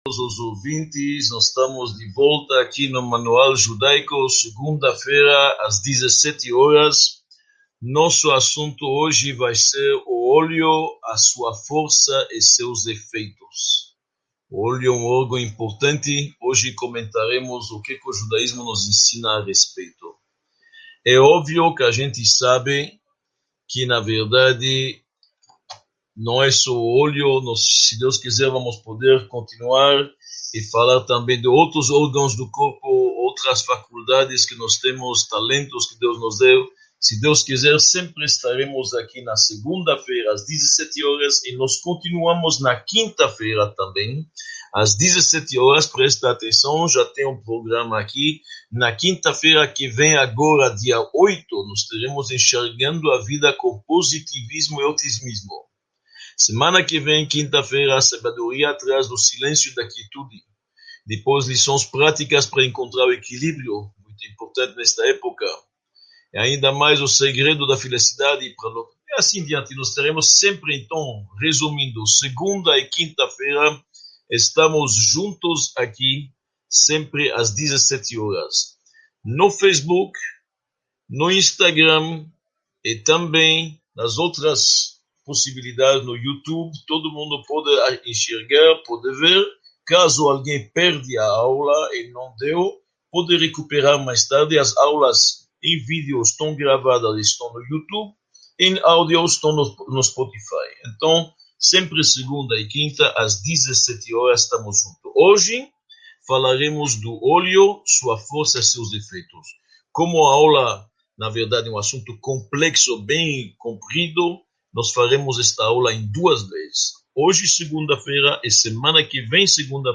28 – Os olhos: sua força e efeitos | Módulo I – Aula 28 | Manual Judaico